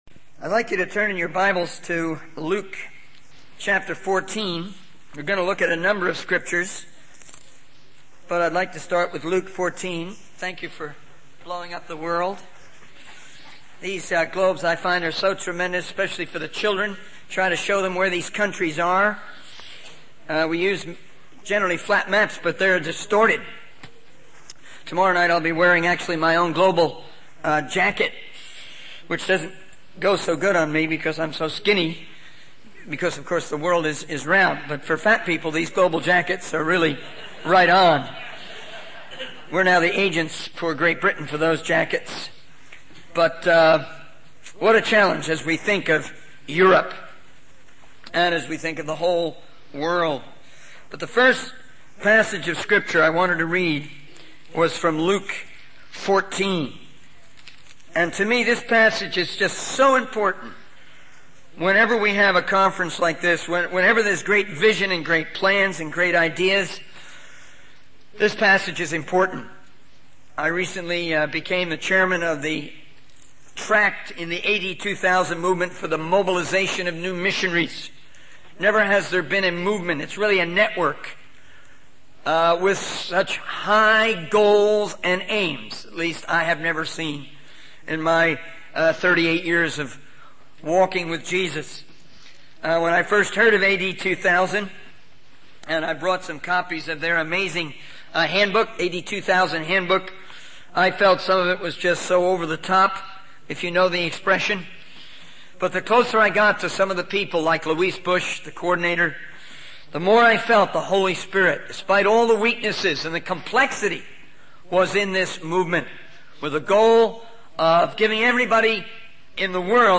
In this sermon, the speaker emphasizes the importance of feeding and overseeing the flock of God with willingness and a ready mind. He encourages humility and submission to one another, as God resists the proud but gives grace to the humble. The speaker also highlights the significance of sending in missions, stating that sending is just as important as going.